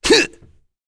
Baudouin-Vox_Attack1.wav